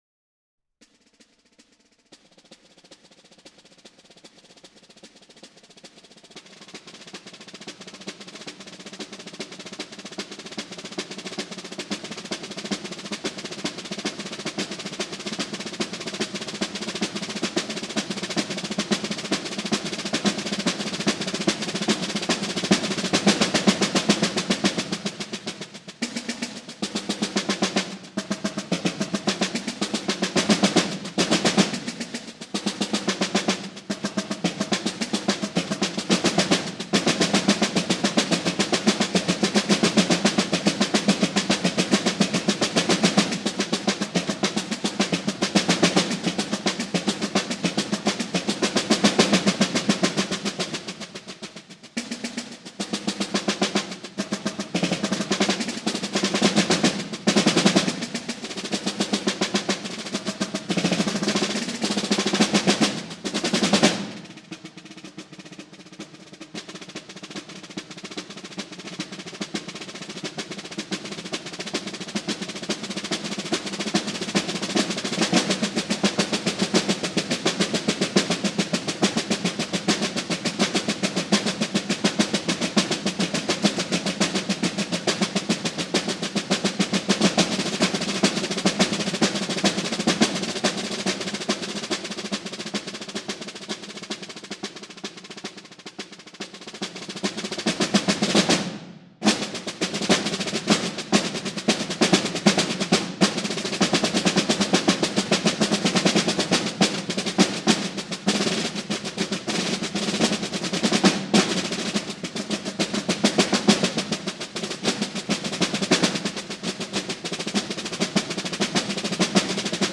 Voicing: Snare Drum Quintet